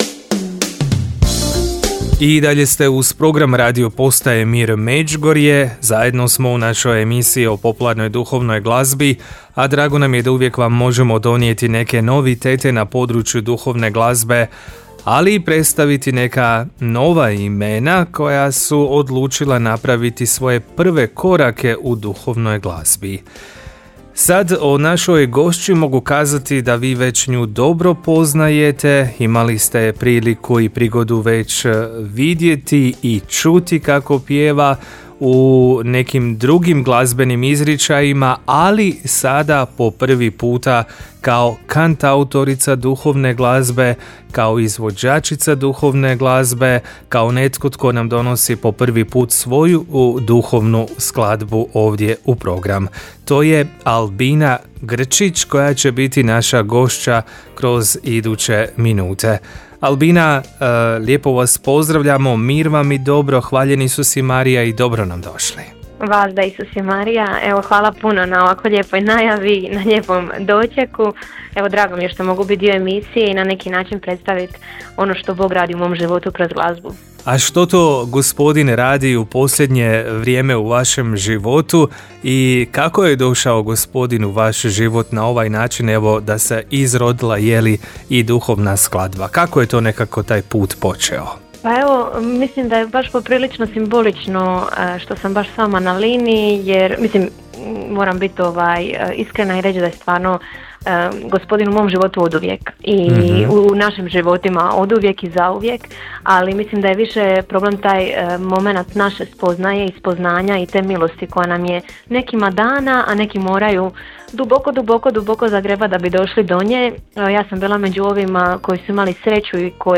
U razgovoru za emisiju o popularnoj duhovnoj glazbi